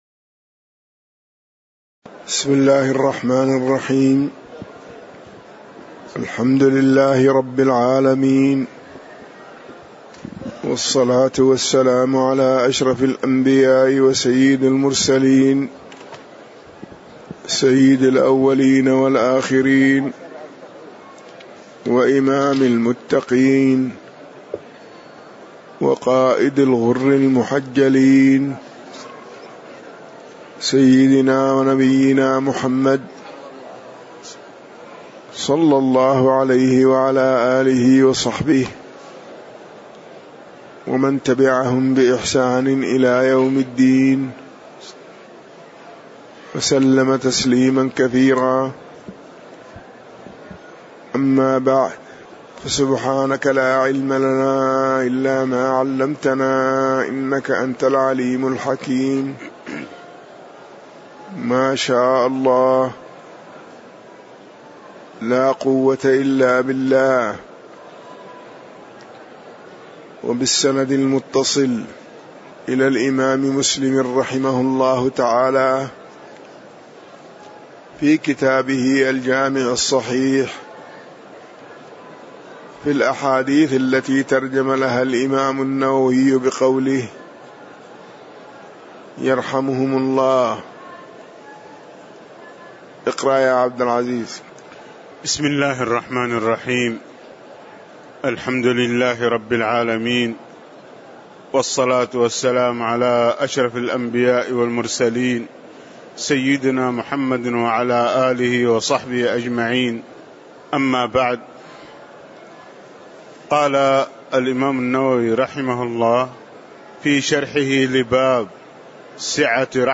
تاريخ النشر ٢٦ ربيع الثاني ١٤٣٨ هـ المكان: المسجد النبوي الشيخ